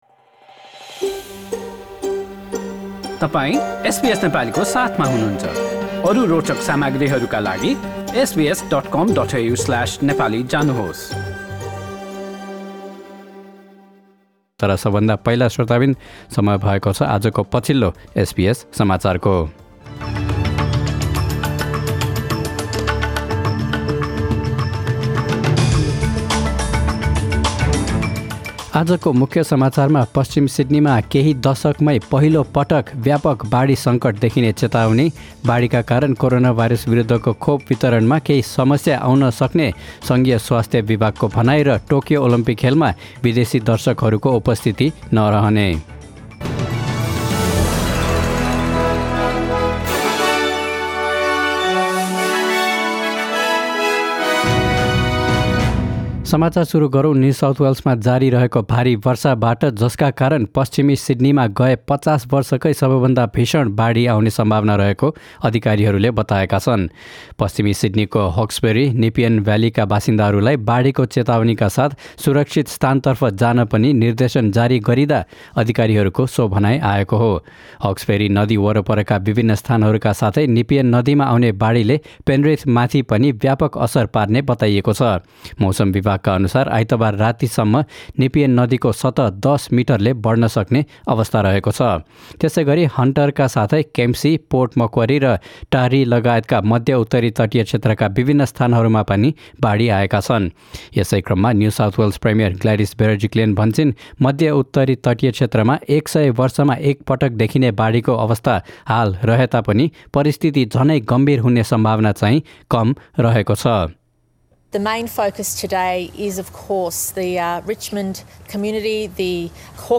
Listen to latest news headlines from Australia in Nepali.